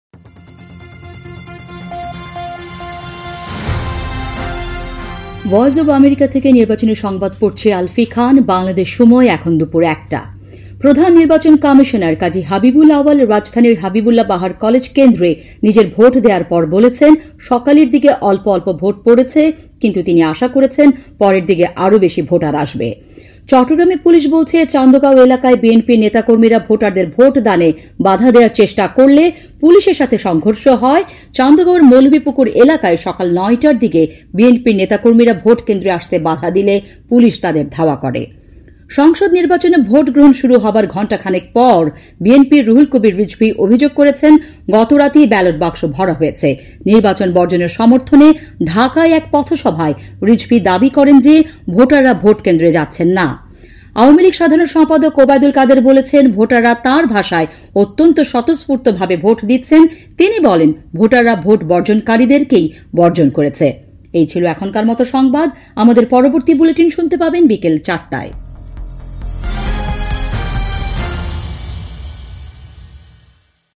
দুপুর ১টার সংবাদ